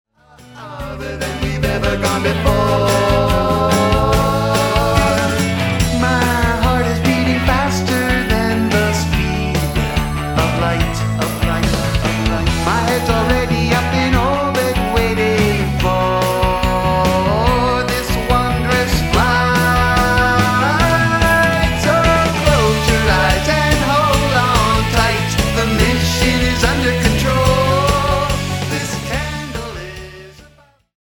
IF YOU'RE A RETRO POP/ROCK MUSIC LOVER,
The end result is mostly upbeat tunes